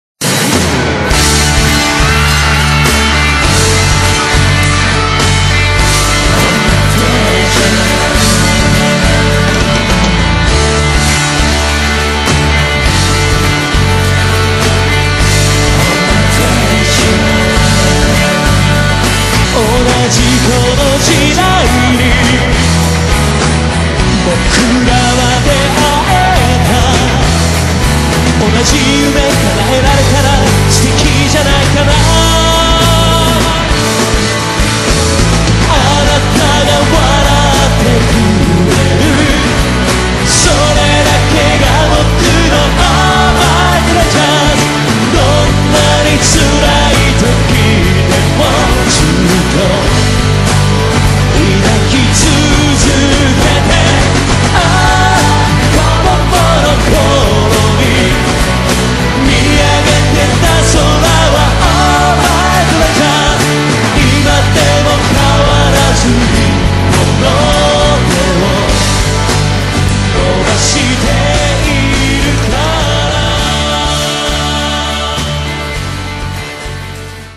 サビ編集